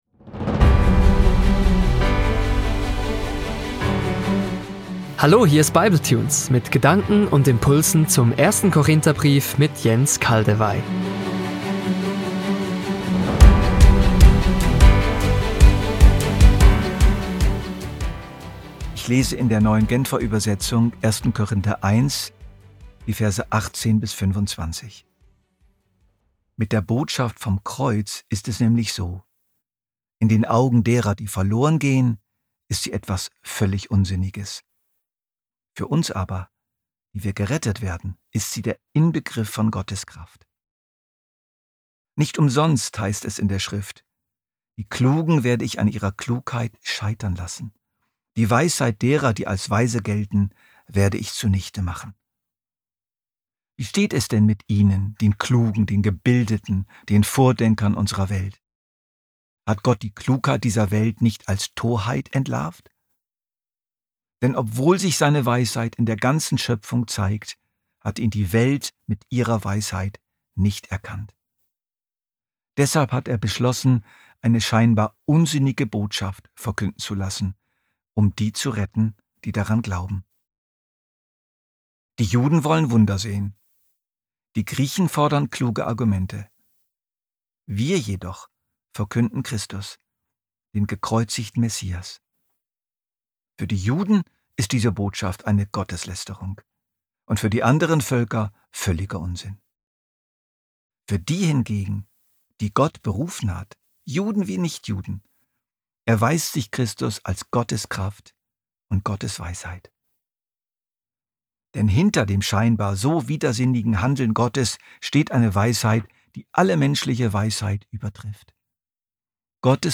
Ein „bibletune“ beinhaltet eine Mischung aus vorgelesenen Bibeltexten und wertvollen Impulsen.